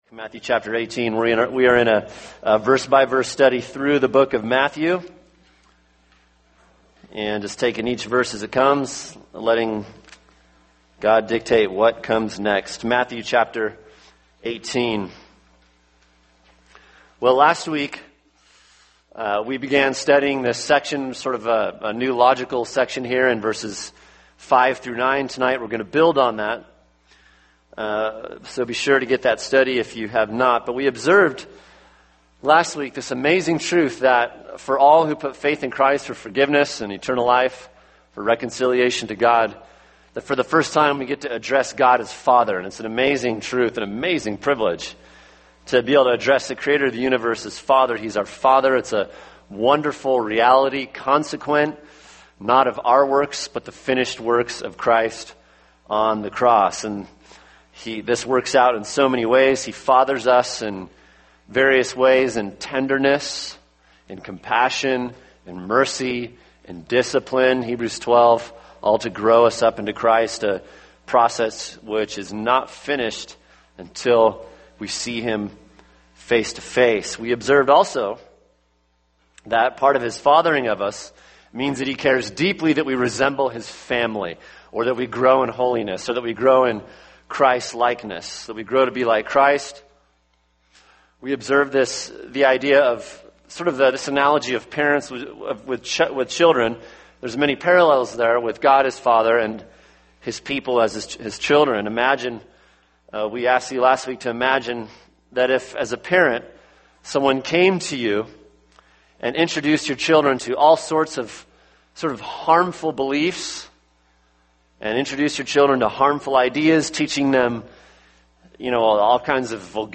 [sermon] Matthew 18:5-9 Sharing God’s Concern for Holiness (Part 2) | Cornerstone Church - Jackson Hole